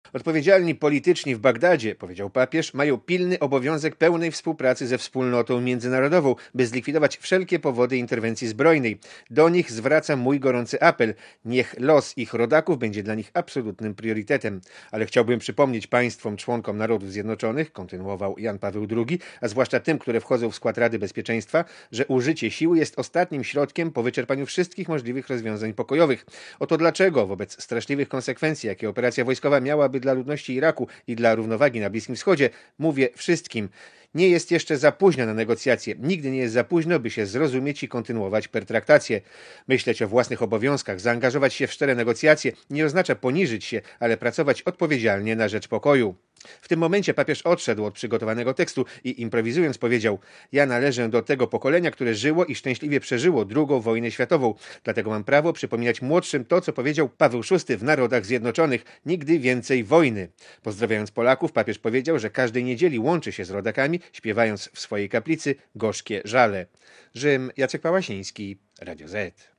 Posłuchaj relacji korespondenta Radia Zet(0.4 MB)